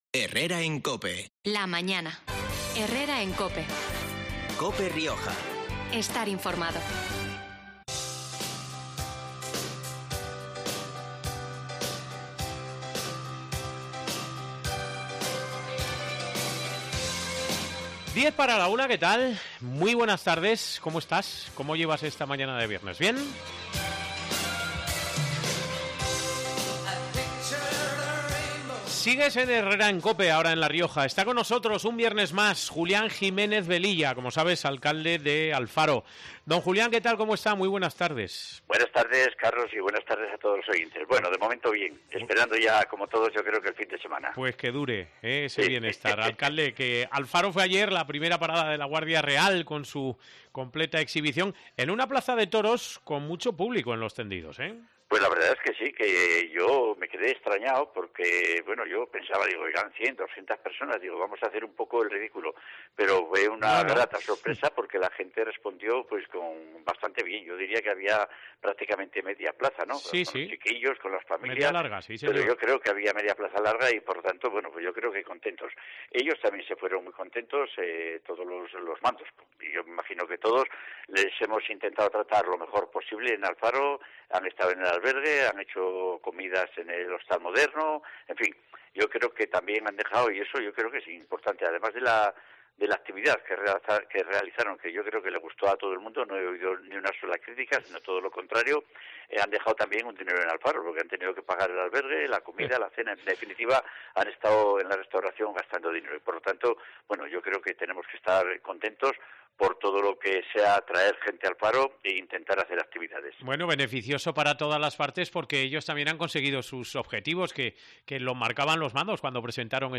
El alcalde alfareño, Julián Jiménez Velilla, repasa las principales citas del programa de las fiestas de primavera, por San Isidro, del 13 al 15 de mayo